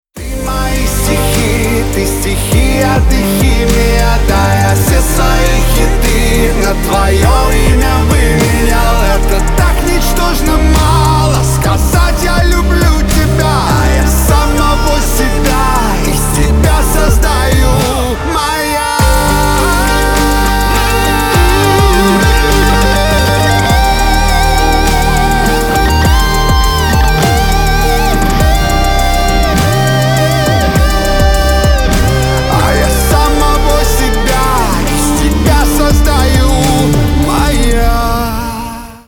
• Качество: 320, Stereo
поп
мужской вокал
громкие
электрогитара